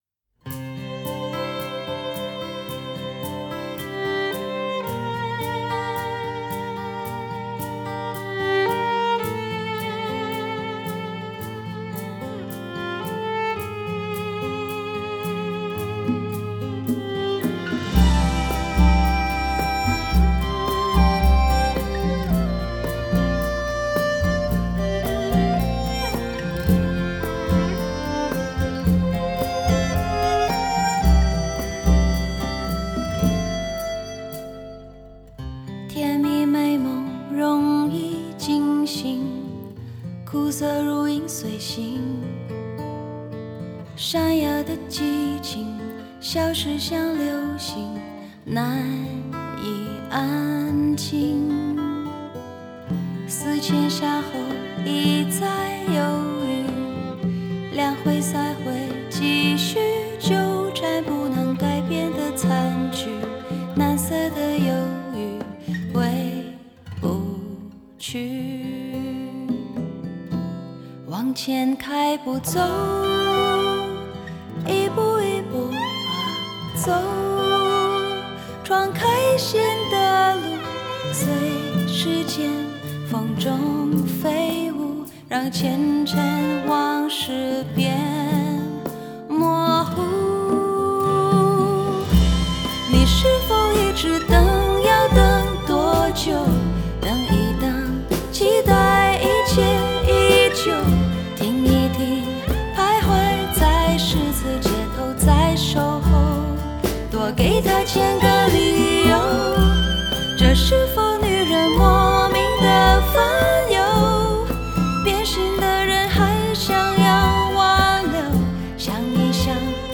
运用最新SUMMING模拟合成技术，24Bit192KHz高采样，双路CD复合直灌母版，
声场宽广，音色纯净